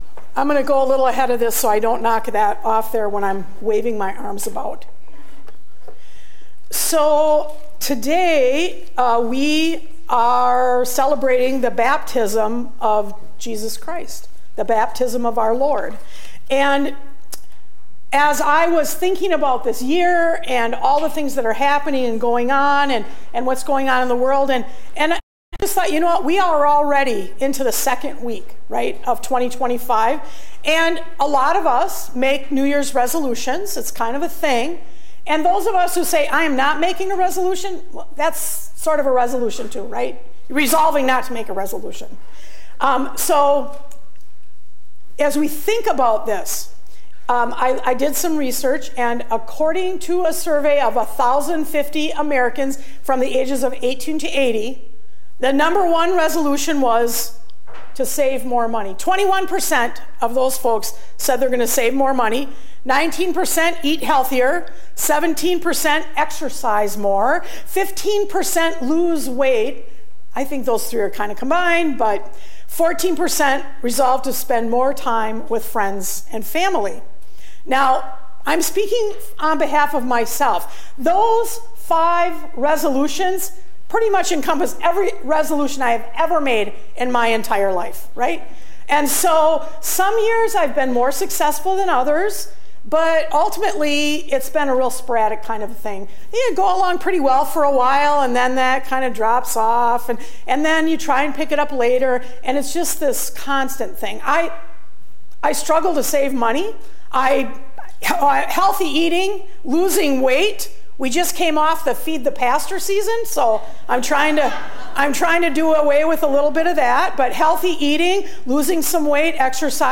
Sermons | Eleva Lutheran Church